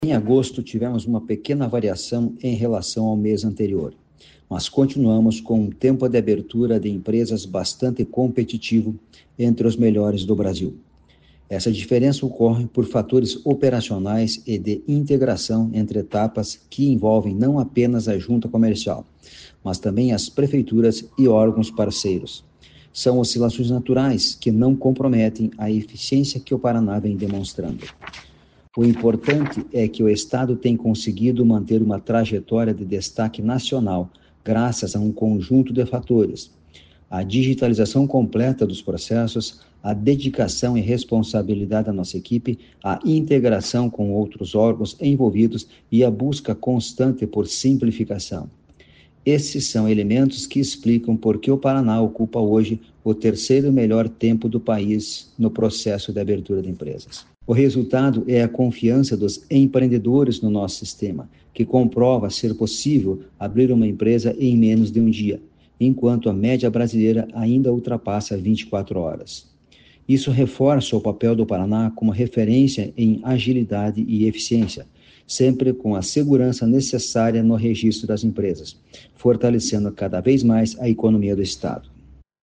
Sonora do presidente da Jucepar, Marcos Rigoni, sobre o tempo de abertura de empresas no Paraná em agosto